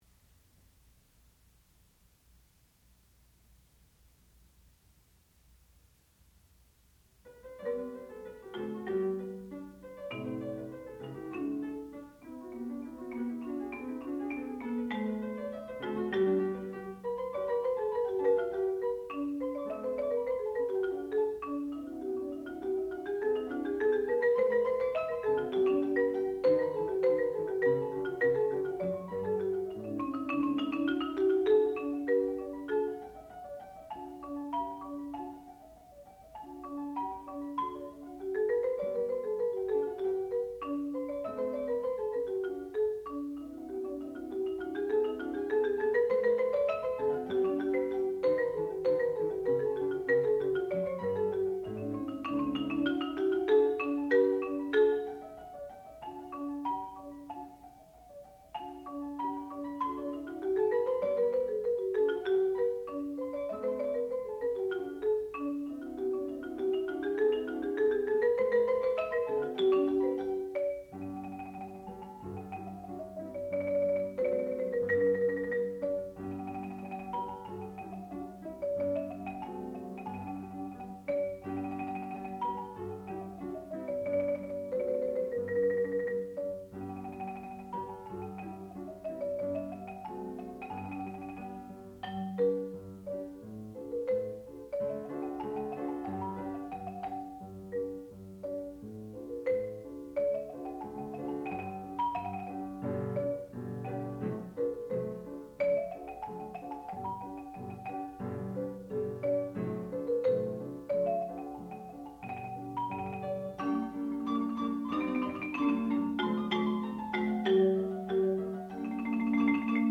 sound recording-musical
classical music
Junior Recital
percussion